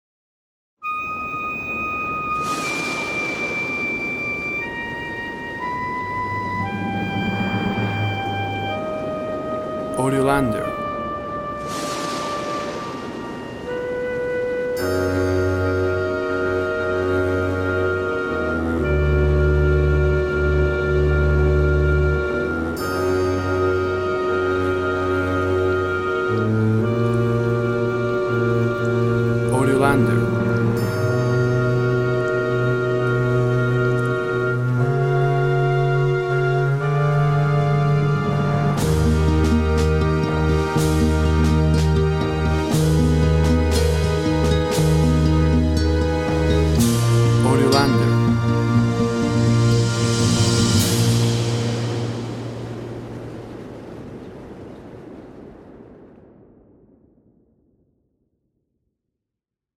A series of music for video games – Incidental.
WAV Sample Rate 16-Bit Stereo, 44.1 kHz
Tempo (BPM) 55